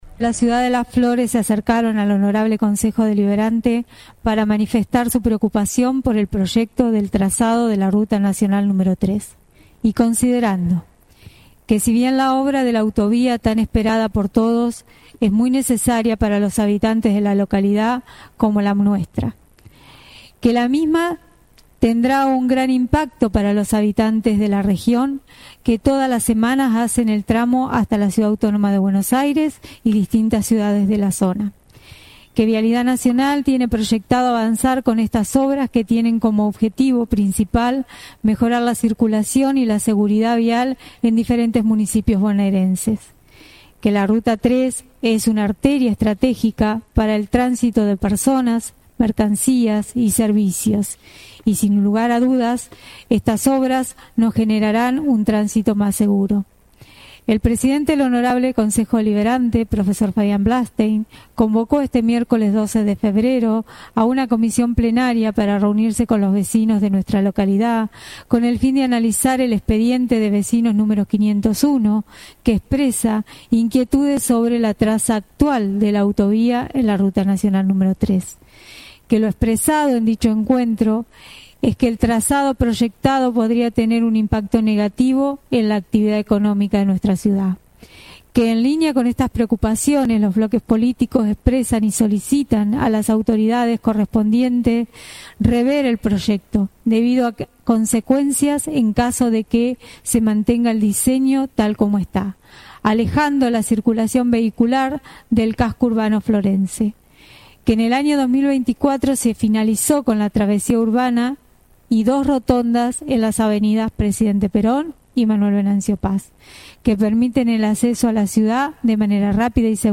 (audio sesión) En la sesión extraordinaria que tuvo lugar días pasados en el salón «Dr. Oscar Alende» fue tratado sobre tablas un expediente que expresa inquietudes sobre la traza actual de la autovía en la Ruta Nacional N°3.